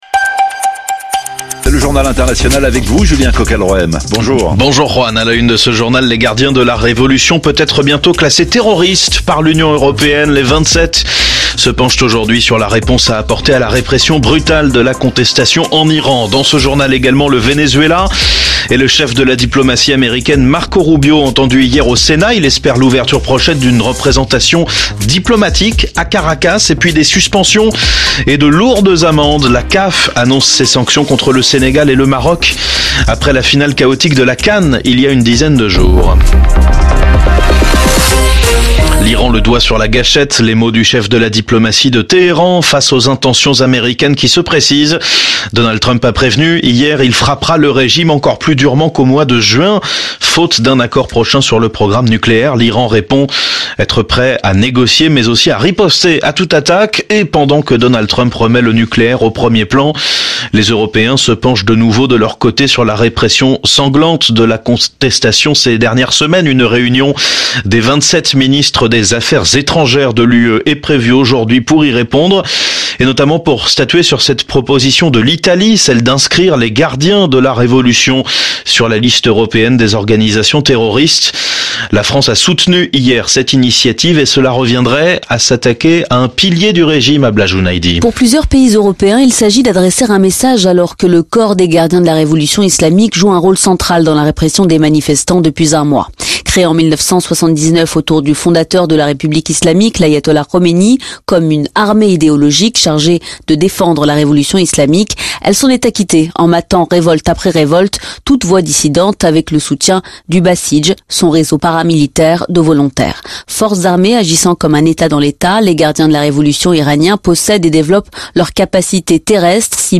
Flash infos 29/01/2026